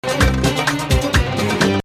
Hijaz 1
descending from flat 6